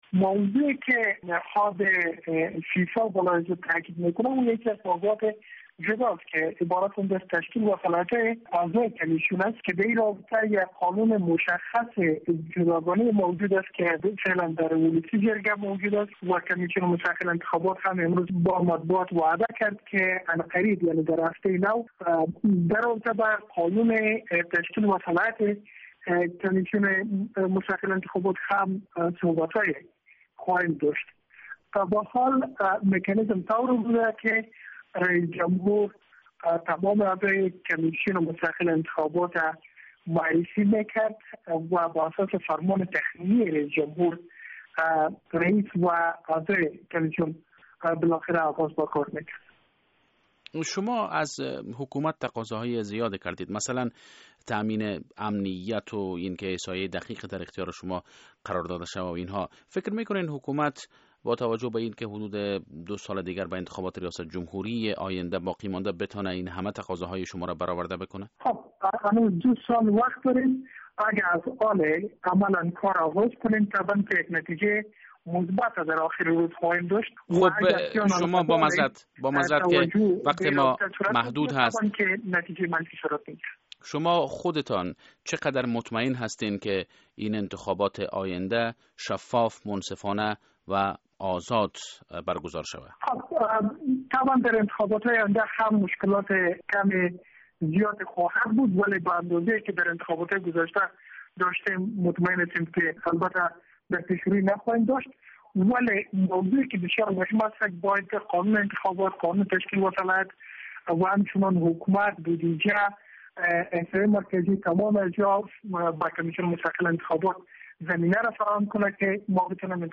در مصاحبهء تیلفونی